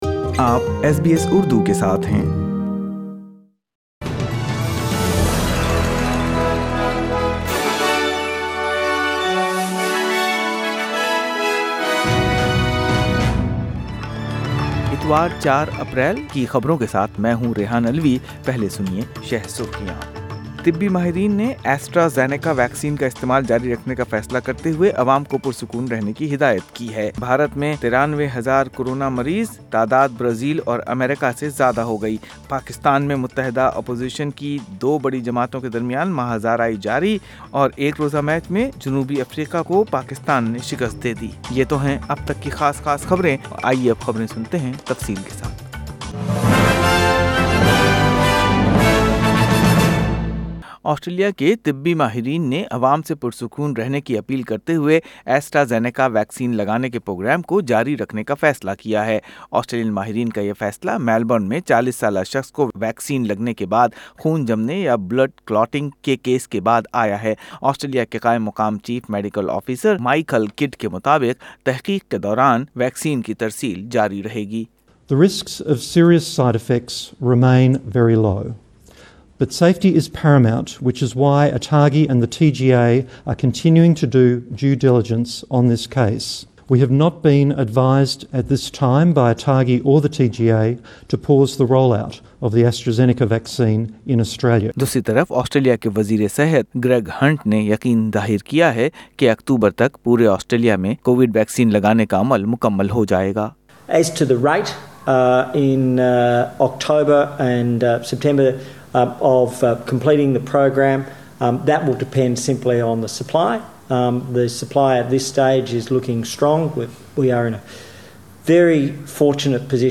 In this bulletin, Federal Health Minister Greg Hunt optimistic the national vaccine rollout will achieve an October target, Australian fashion icon Carla Zampatti to be honoured with a state funeral, Record surge of Covid cases in India.